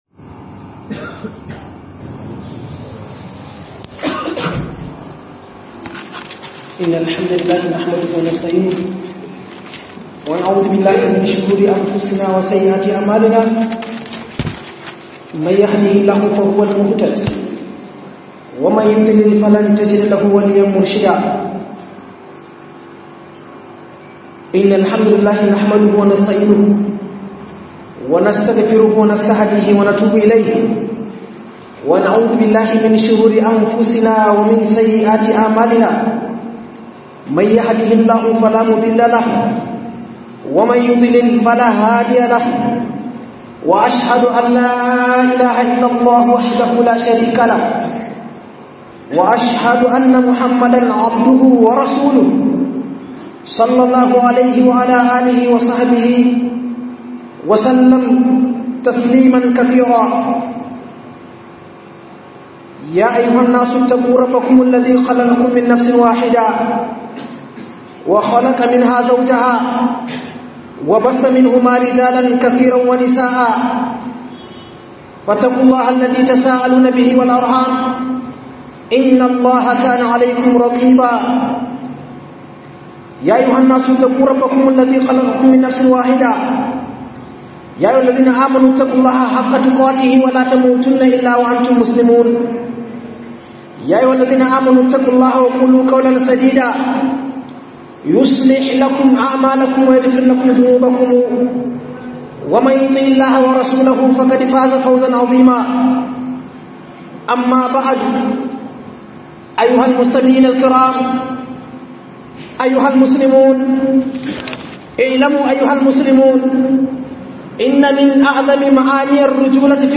Mai Kayiwa Addinin Musulunci a Rayuwarka - HUDUBA